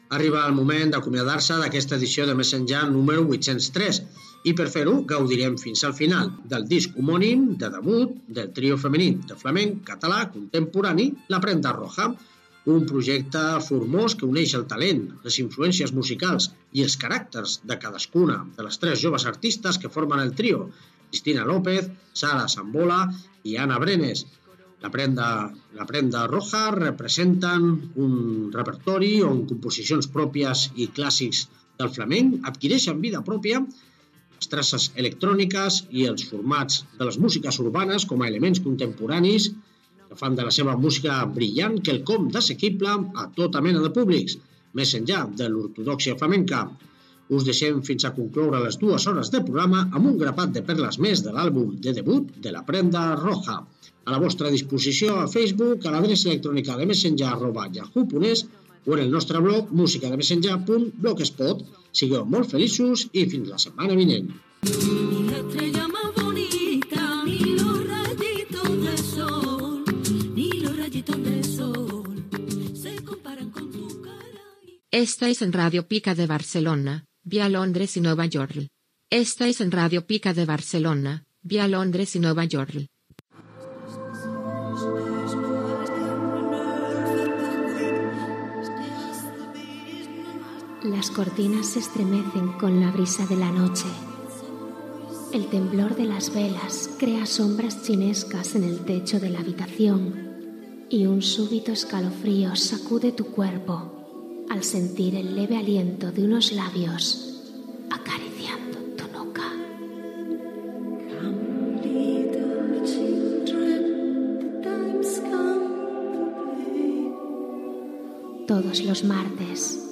Comiat del programa, tema musical, identificació de la ràdio, promoció "En el corazón de las tinieblas"
Musical